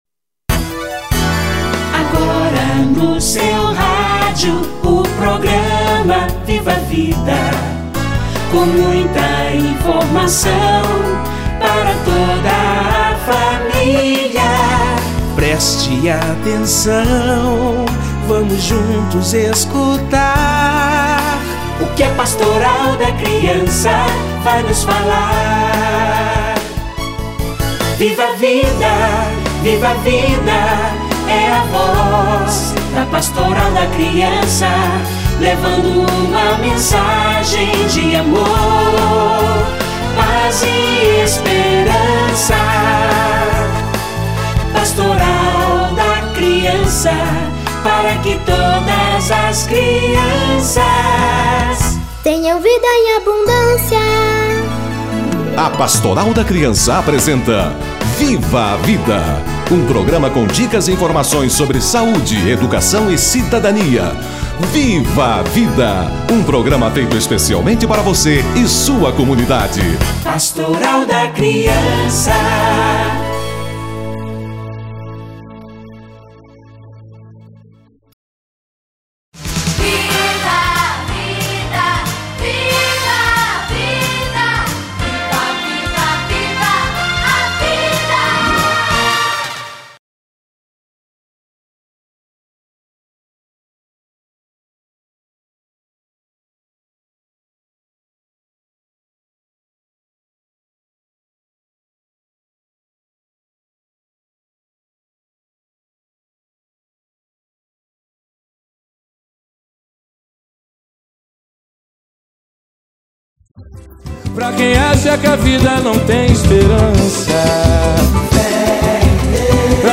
Dia de Oração e Ação pela Criança - Entrevista